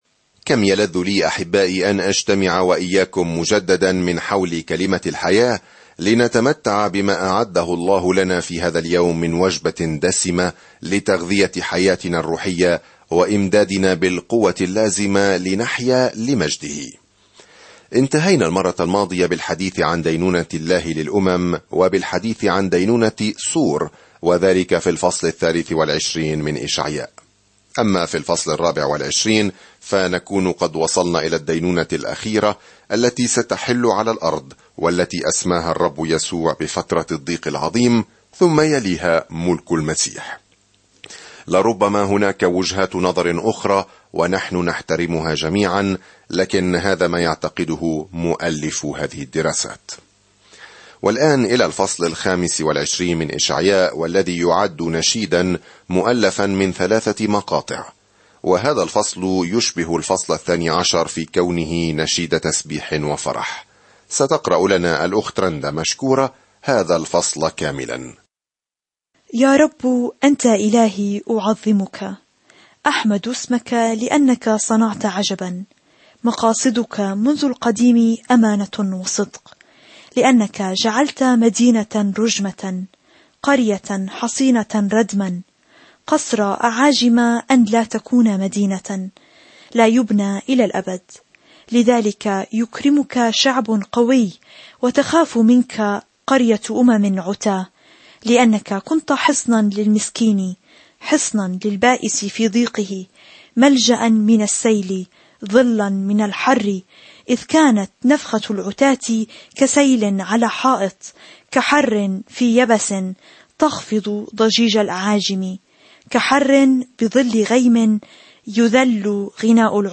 سافر يوميًا عبر إشعياء وأنت تستمع إلى الدراسة الصوتية وتقرأ آيات مختارة من كلمة الله.